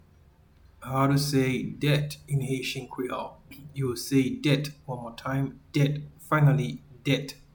Pronunciation:
Debt-in-Haitian-Creole-Det.mp3